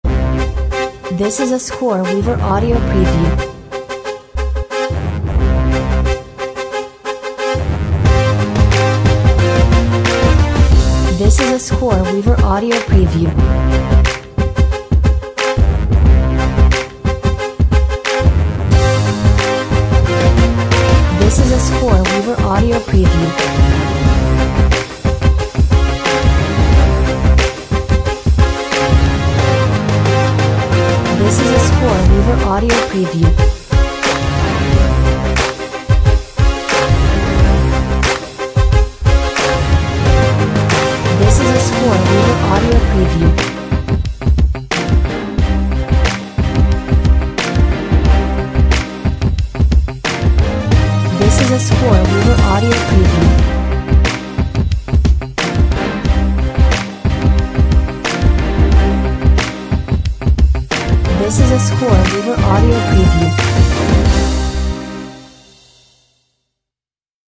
Great for motivating people with explosive energy!